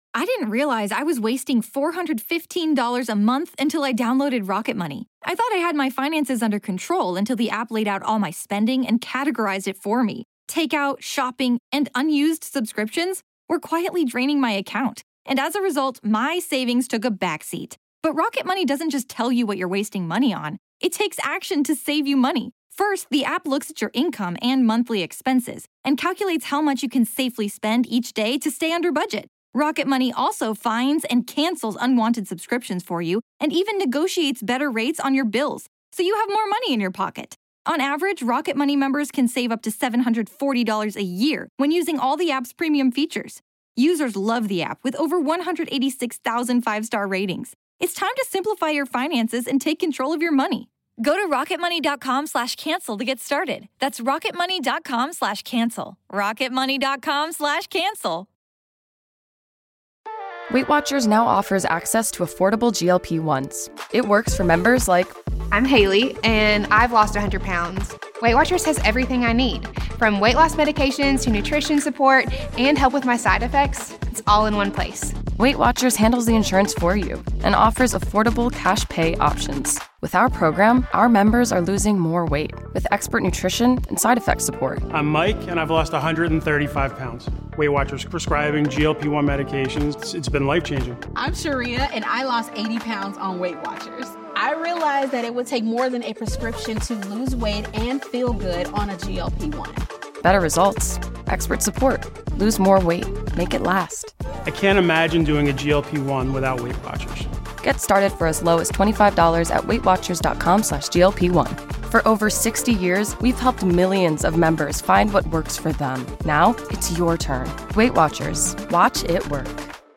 In today’s episode of Assumptions we have Psychics confront your assumptions about their abilities.